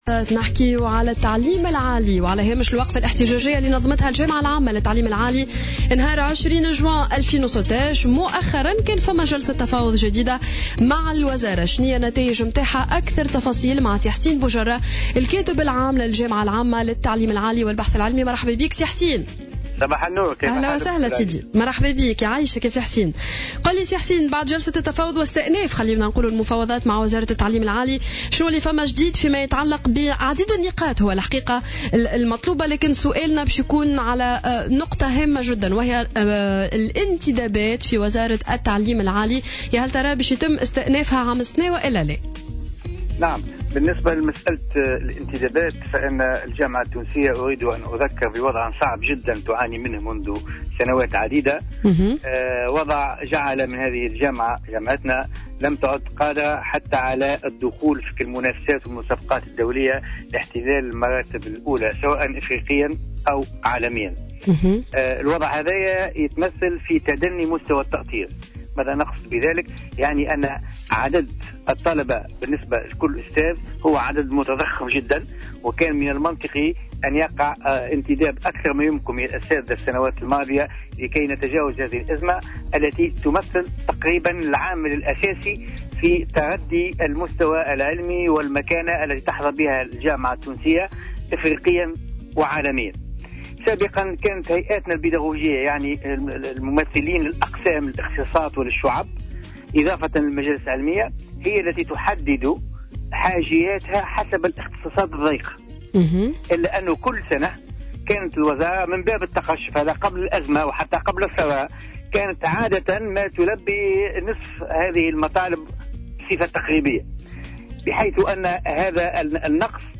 وأضاف في مداخلة له اليوم في برنامج"صباح الورد" على "الجوهرة أف ام" أنه تم الاتفاق على فتح باب الانتداب في سلك التعليم العالي والبحث العلمي وذلك خلال جلسة تفاوضية بين الطرف النقابي والوزارة انعقدت إثر الوقفة الاحتجاجية التي نظمها الأساتذة والنقابيون أمام مقر الوزارة.